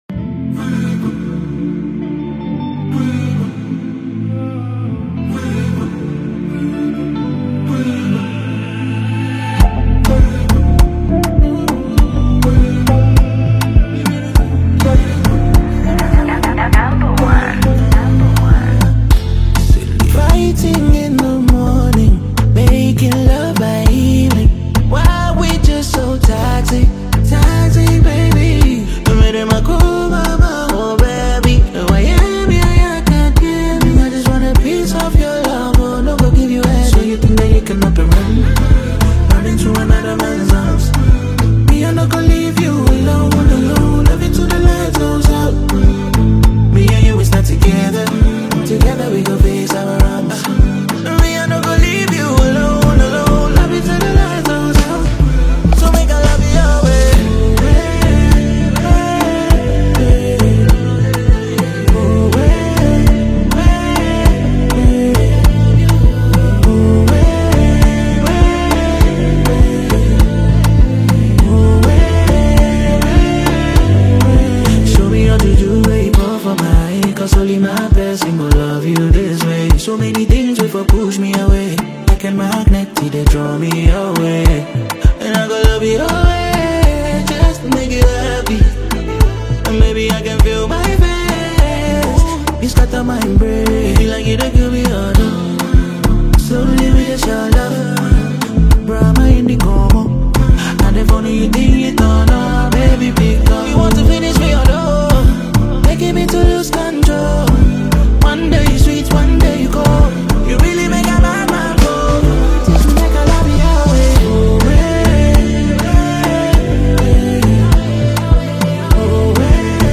smooth and captivating performance
Genre: Afrobeats / Afropop